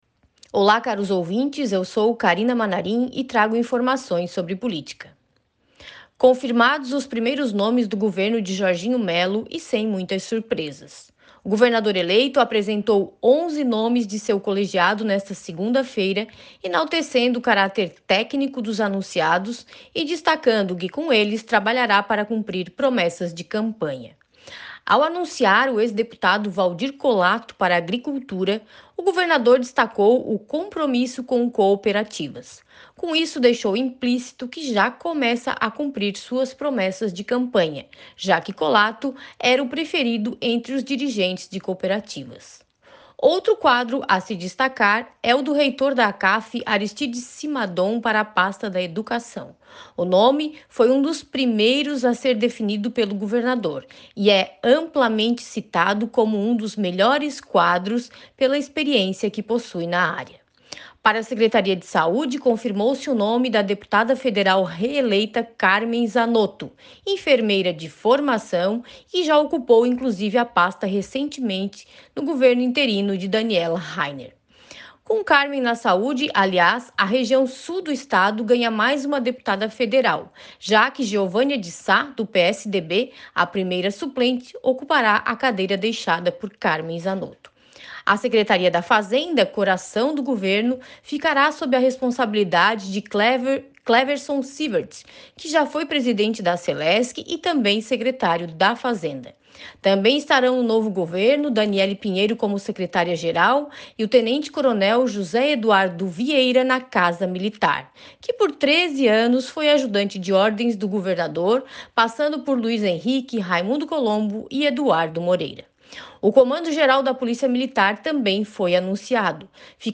Jornalista comenta sobre os principais nomes que assumirão importantes pastas do futuro governo do Estado